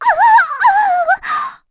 Erze-Vox_Damage_kr_03.wav